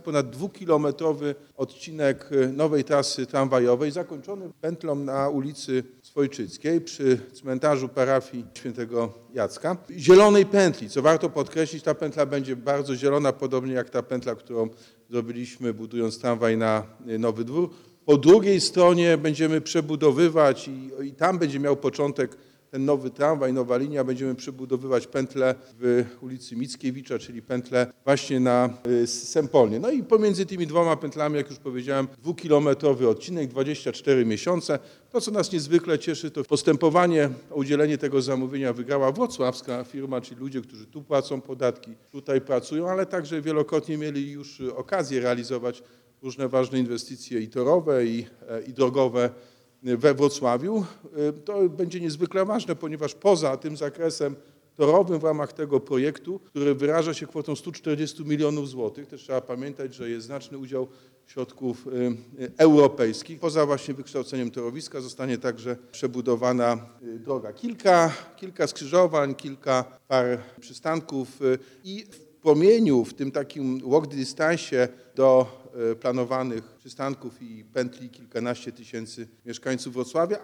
O szczegółach inwestycji mówi prezydent Wrocławia – Jacek Sutryk.
na-strone_2_Sutryk-o-inwestycji.mp3